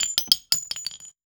weapon_ammo_drop_21.wav